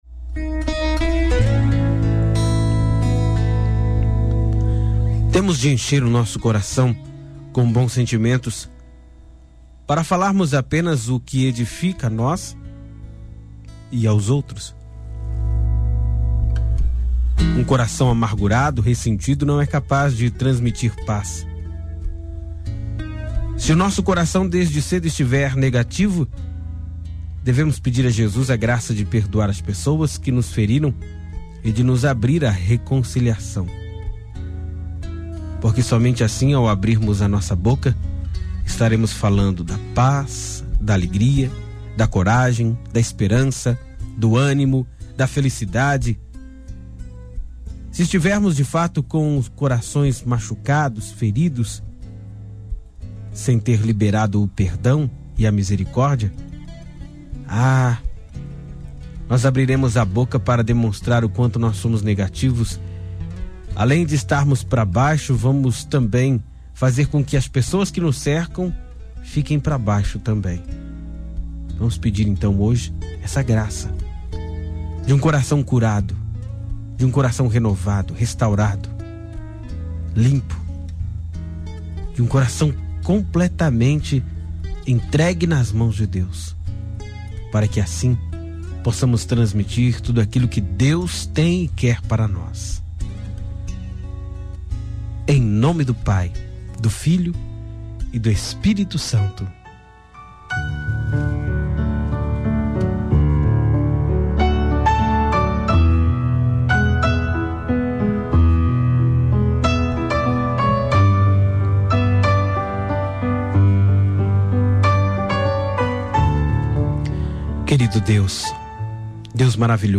Oração: Raiz de Amargura